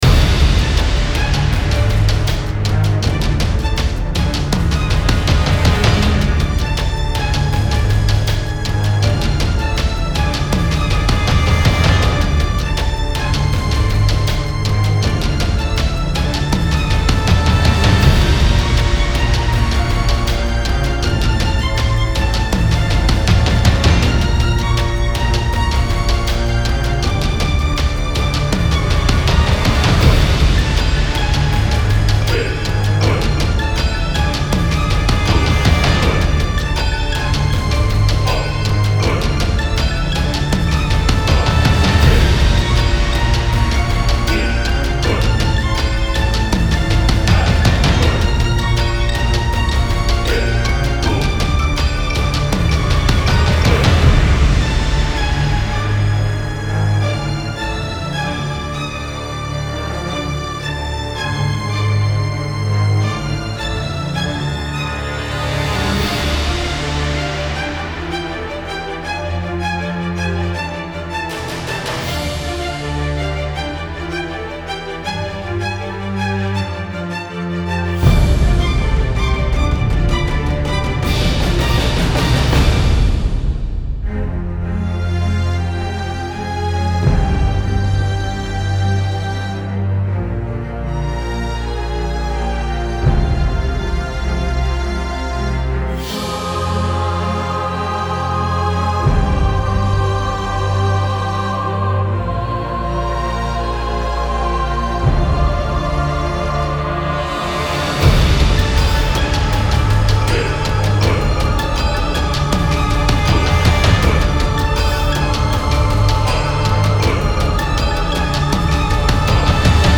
Style Style Orchestral, Soundtrack
Mood Mood Aggressive, Epic, Uplifting
Featured Featured Bass, Brass, Choir +2 more
BPM BPM 160